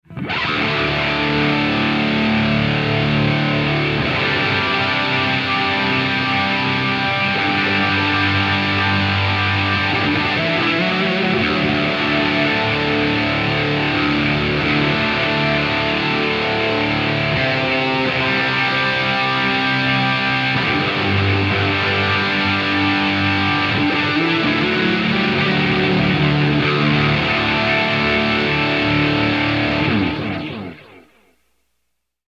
DIG is a killer dual rack delay in a pedal. Three delay machines, run two at once, with easy note subdivisions and tap tempo.